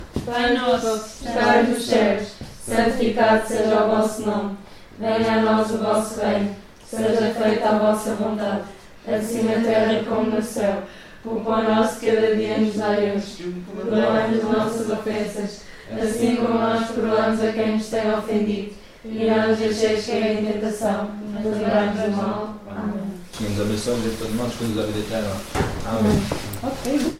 Mas antes de qualquer atividade, não pode faltar a reza.